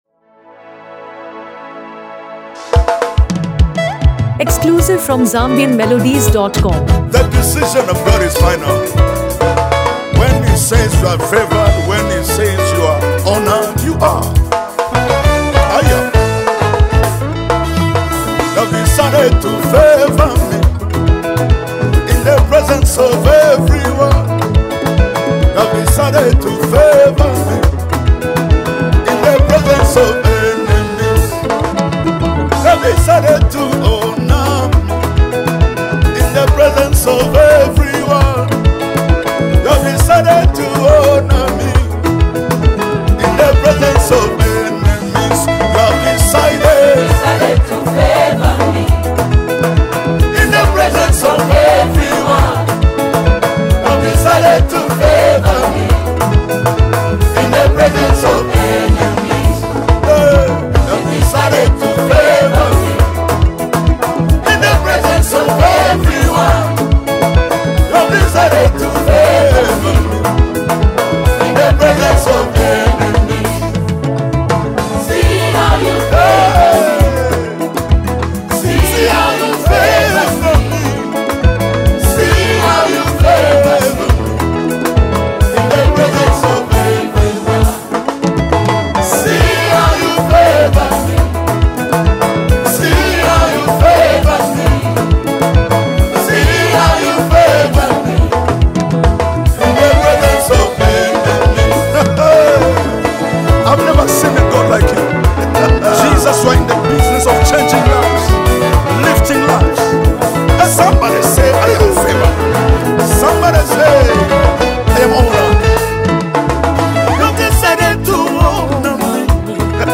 Soul-Stirring Gospel in Mp3 Format
With rich instrumentation